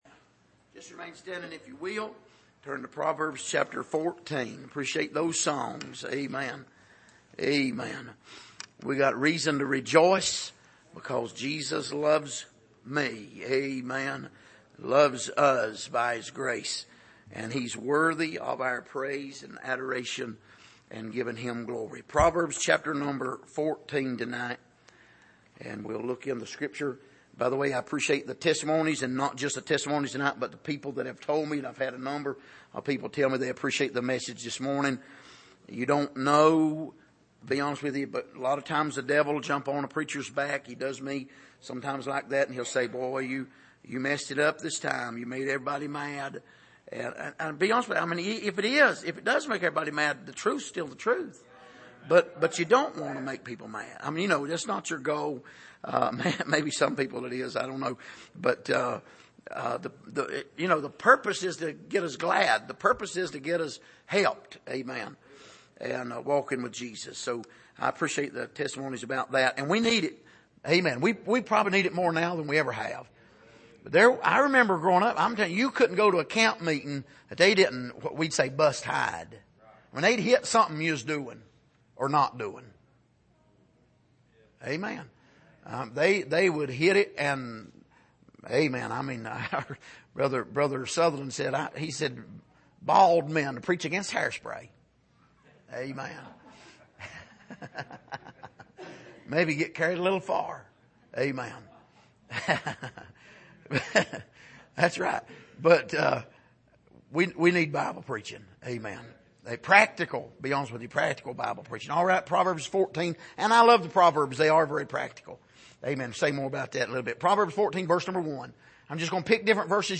Passage: Proverbs 14:1,8,10,12,15,26-27,30,34 Service: Sunday Evening